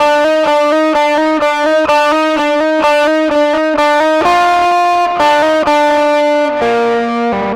Track 10 - Guitar 05.wav